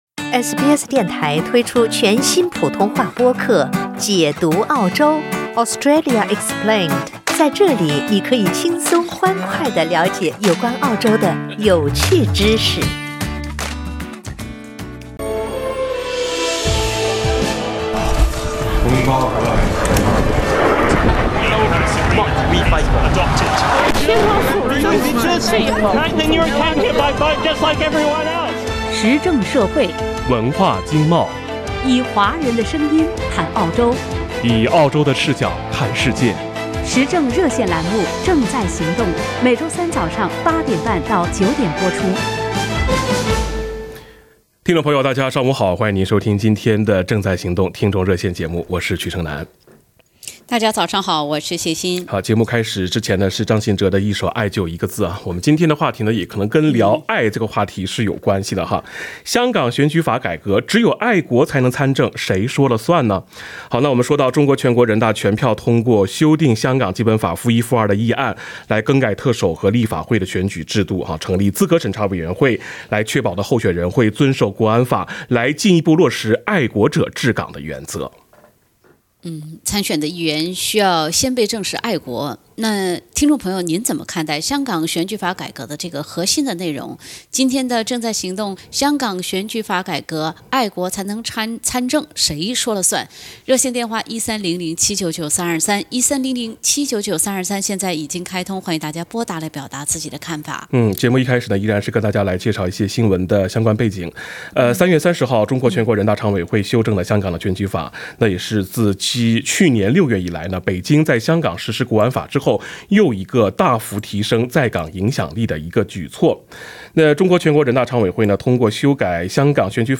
（点击封面图片，收听完整热线节目）